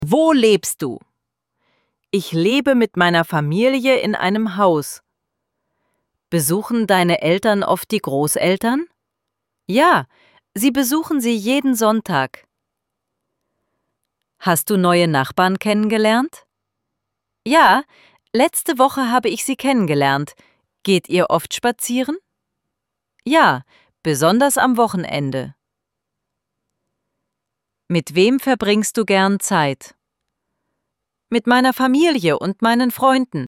IZGOVOR – PITANJA I ODGOVORI:
ElevenLabs_Text_to_Speech_audio-69.mp3